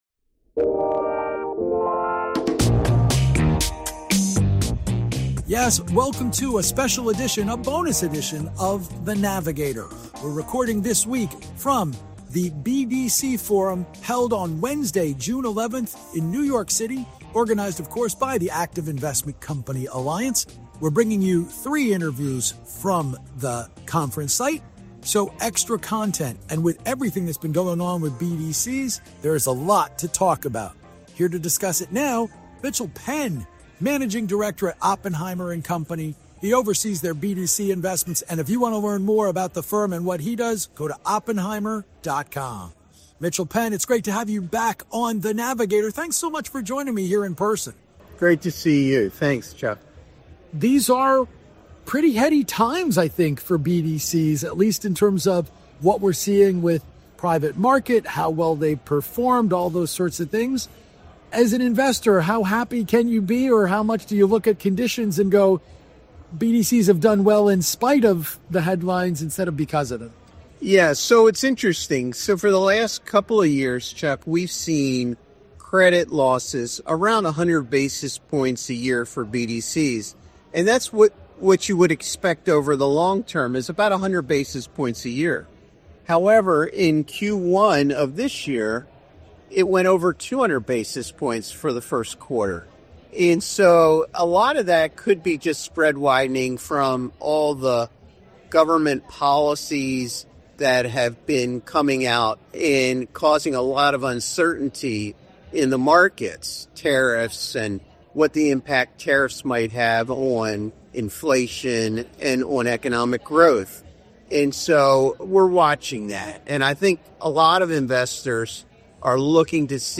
interviewed at the Active Investment Company Alliance BDC Forum in New York on Wednesday